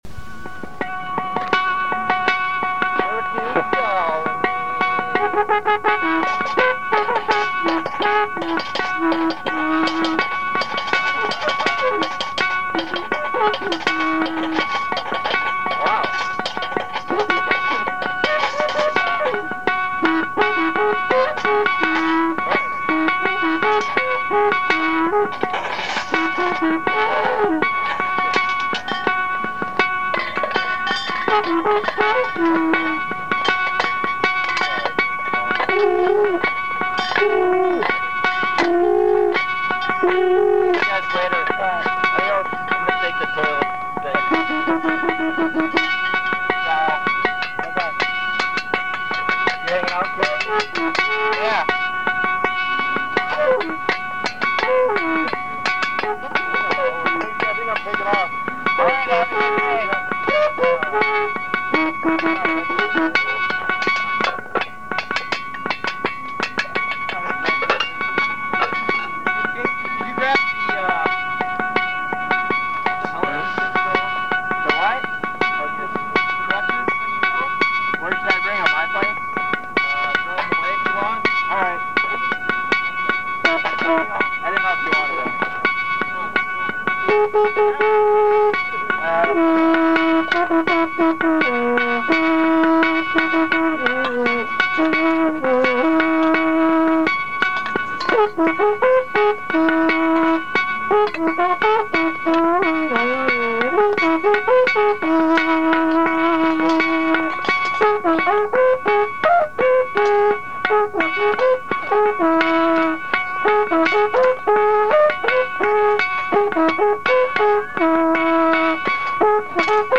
Location: Stevens Square/Red Hot Art